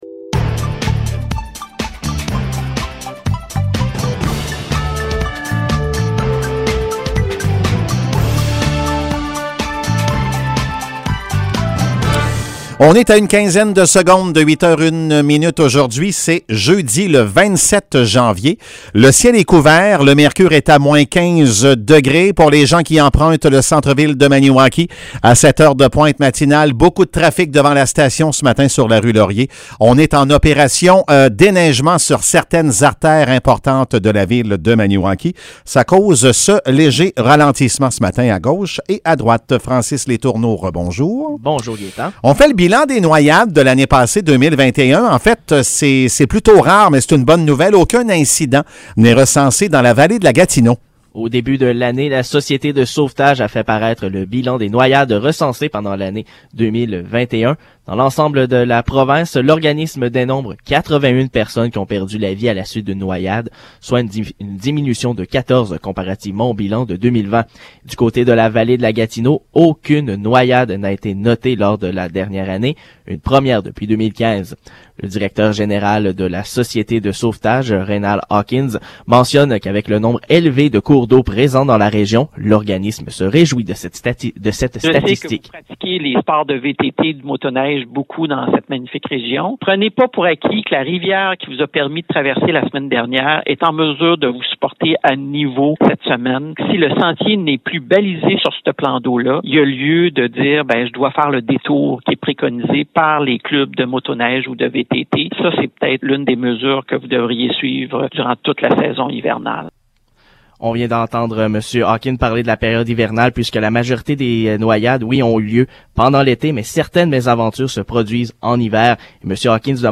Nouvelles locales - 27 janvier 2022 - 8 h